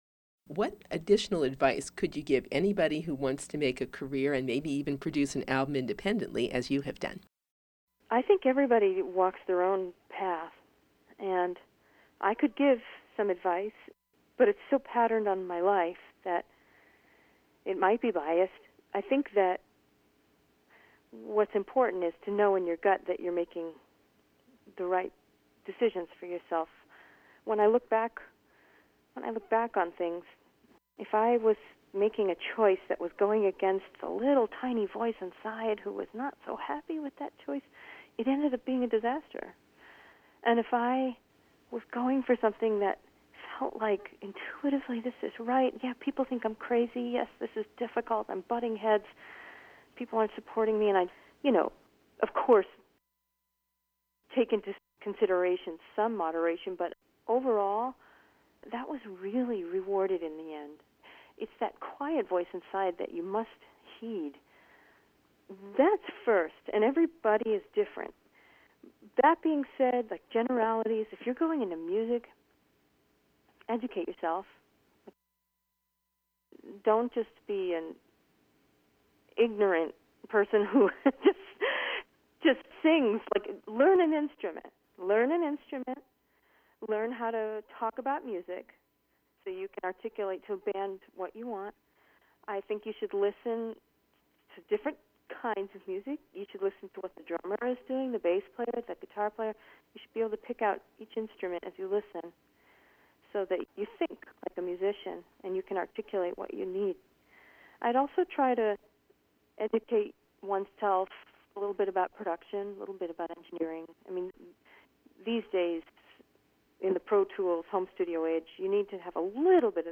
Paula Cole Interview, Part Five